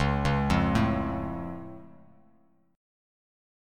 Listen to C#+ strummed